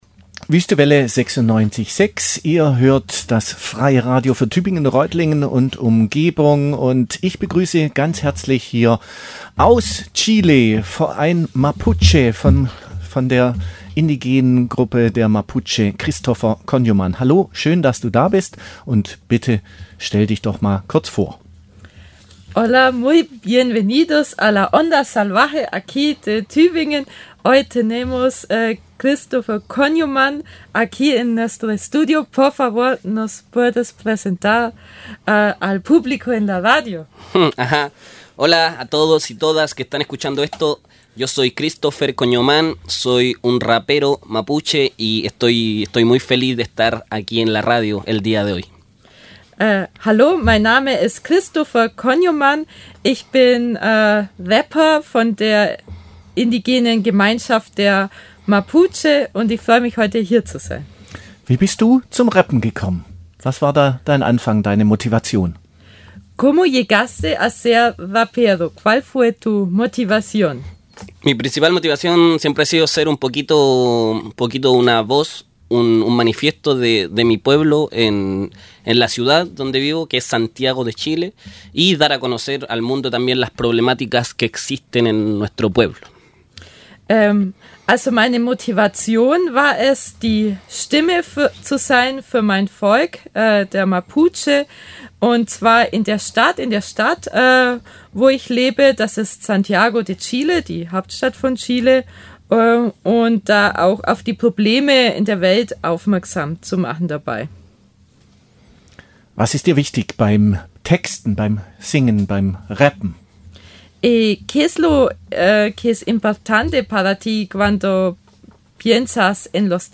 Mapuche Rapper
zu den Beats von zwei Songs